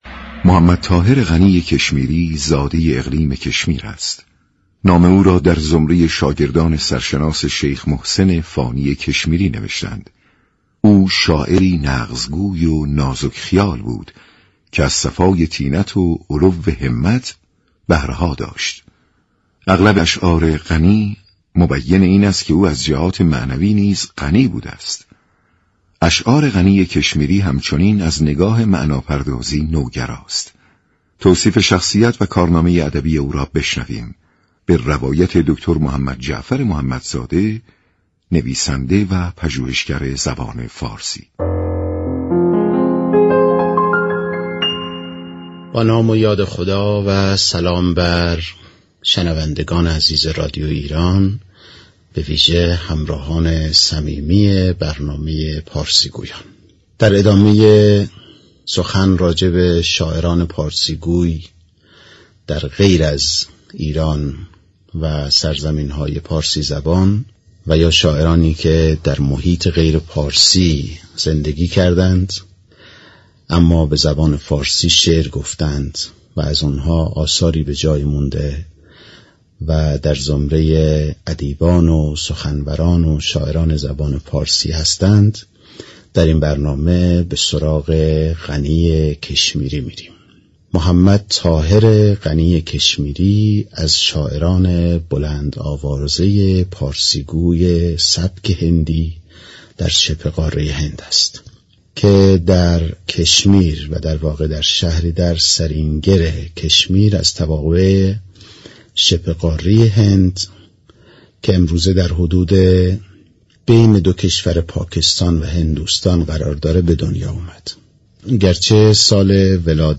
نویسنده و پژوهشگر زبان و ادب فارسی در گفت و گو با رادیو ایران